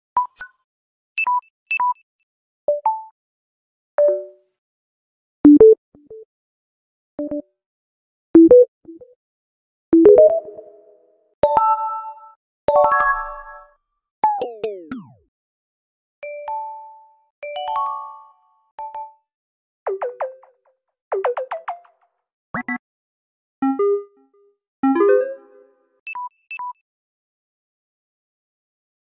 Evolution of Samsung Charging and Low Battery Sound